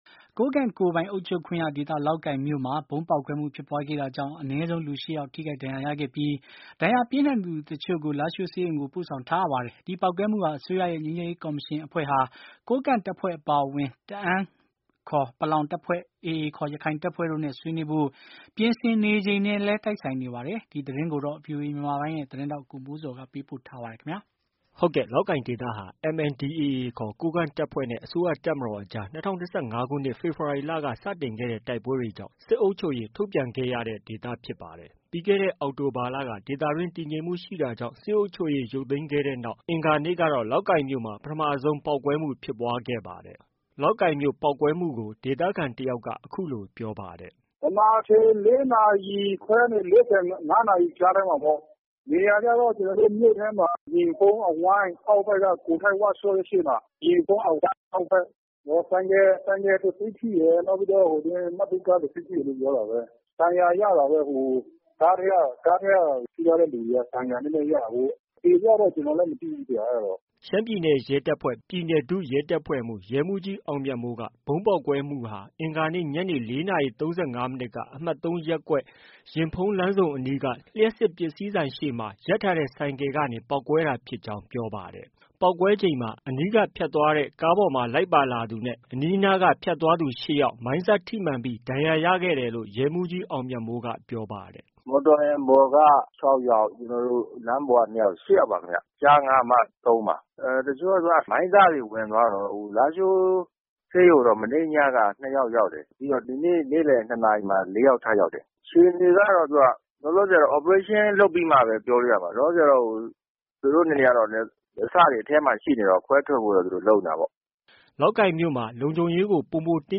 လောက်ကိုင်မြို့ ပေါက်ကွဲမှုကို ဒေသခံတယောက်က အခုလို ပြောပါတယ်။